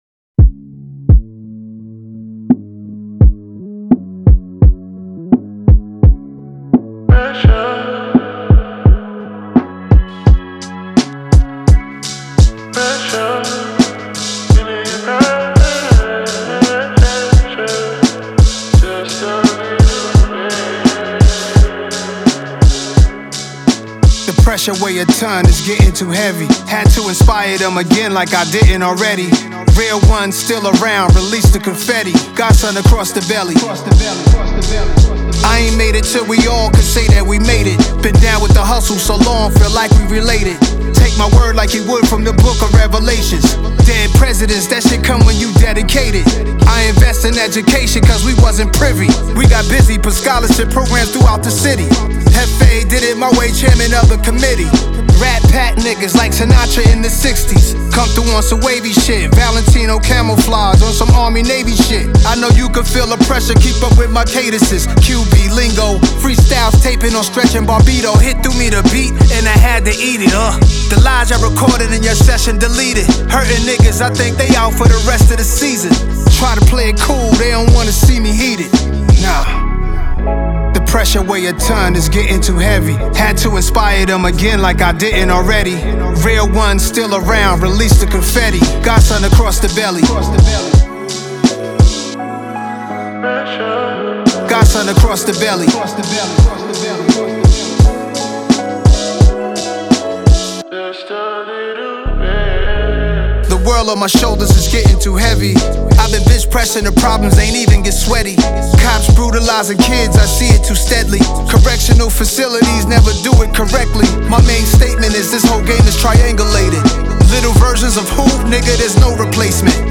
موسیقی رپ رپ خارجی